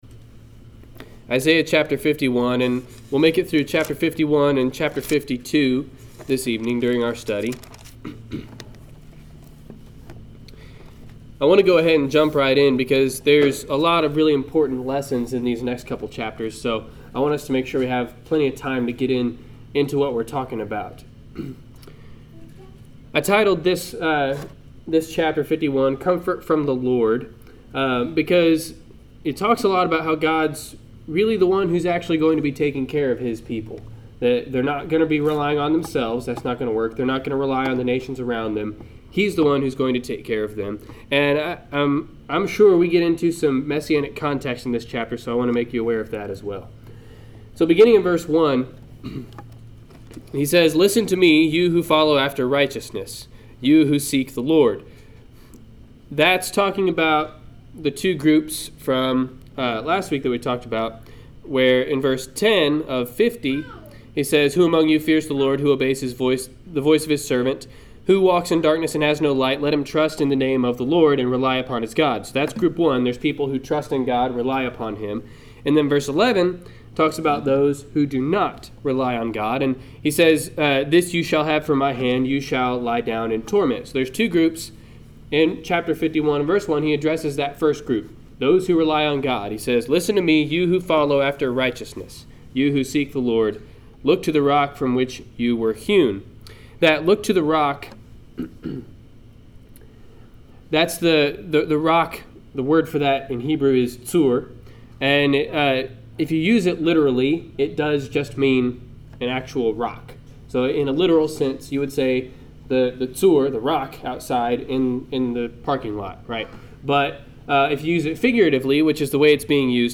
Isaiah 51-52 Service Type: Wednesday Night Class Download Files Notes Notes Notes Notes Topics